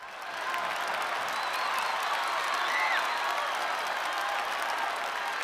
Ashland Legends Cheer
ashland-legends-cheer.mp3